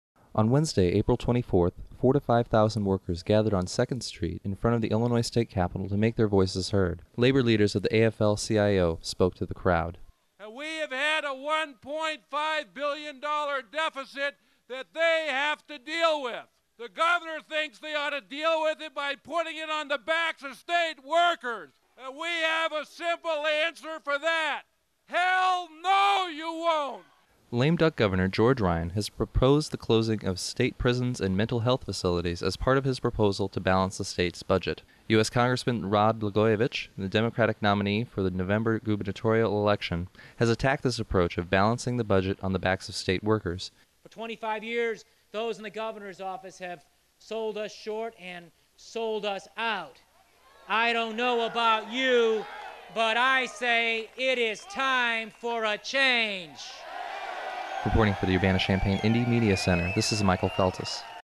springfield_labor_rally_headline.mp3 (838 k)
Here is the FULL headline for Free Speech Radio News. It got chopped in half during broadcast; too many headlines, I guess.